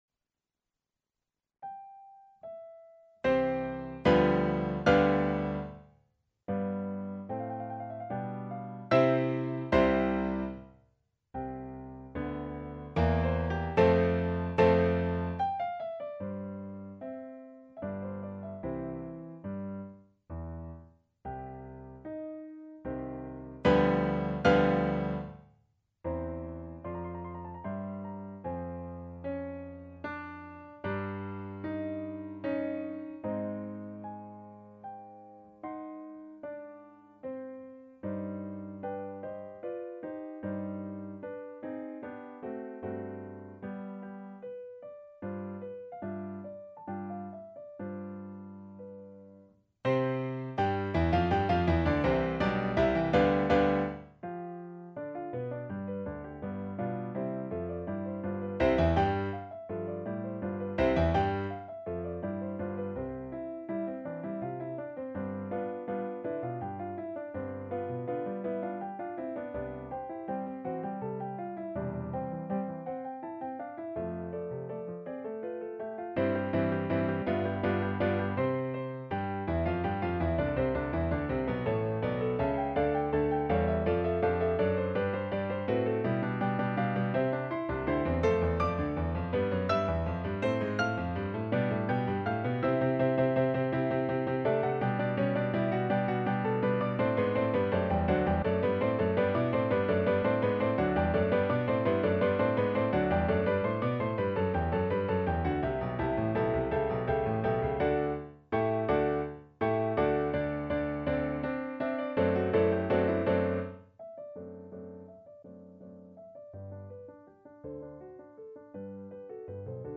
Symphonie en do majeur, dite « de Iéna »